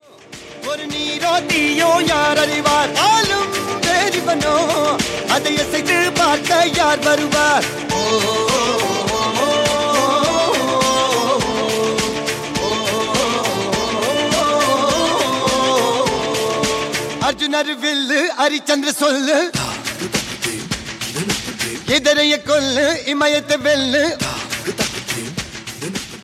loud, clear sound
best flute ringtone download